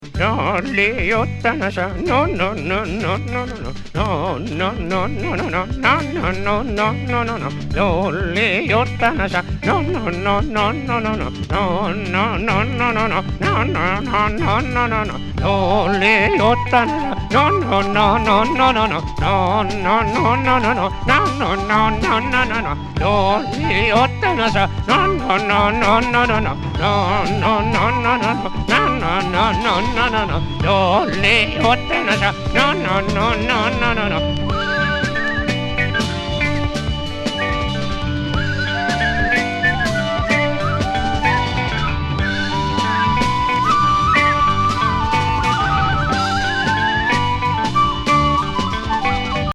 サイケ・グルーヴ。